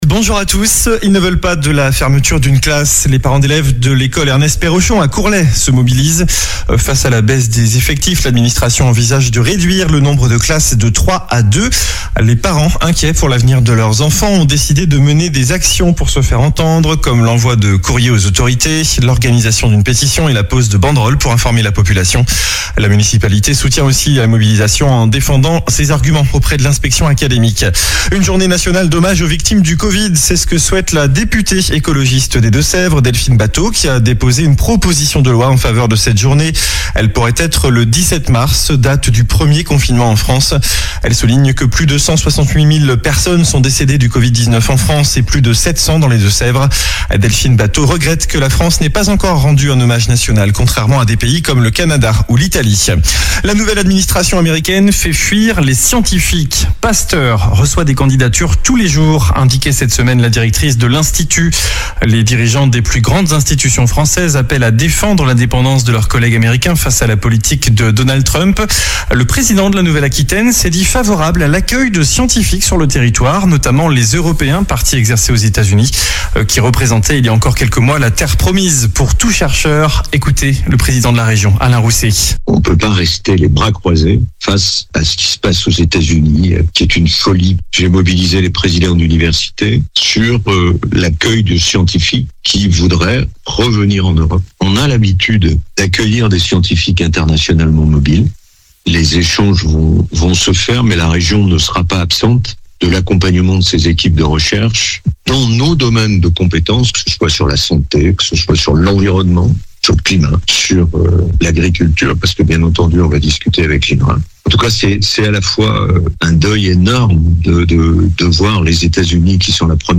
Journal du samedi 15 mars